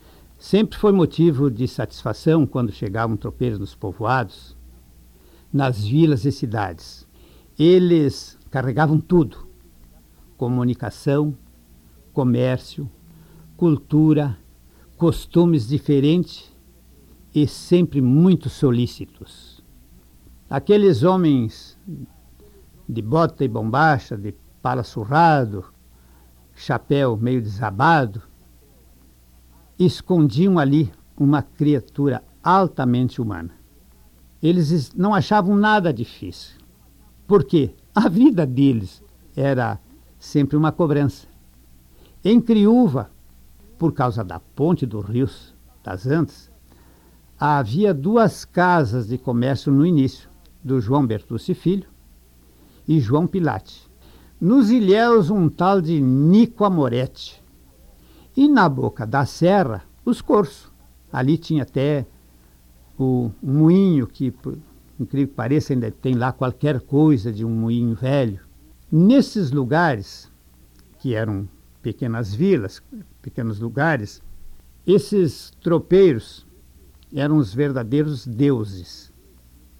Trecho de áudio da entrevista